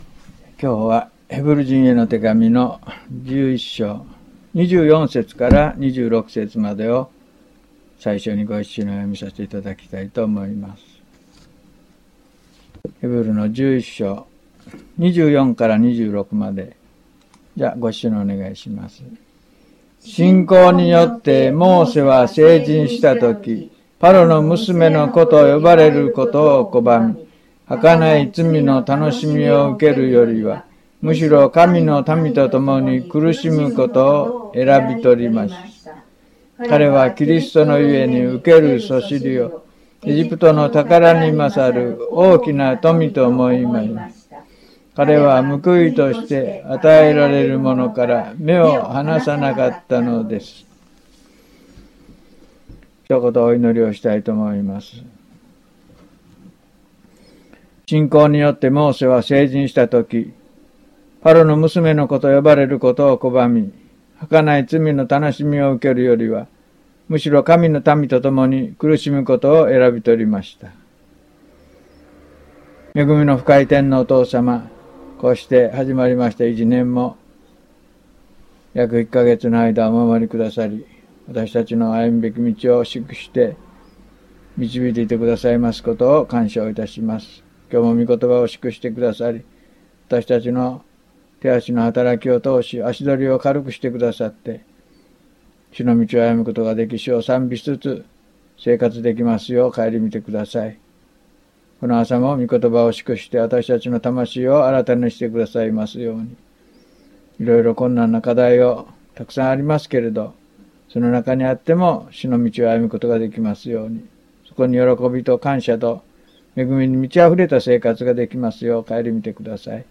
はじめのお祈り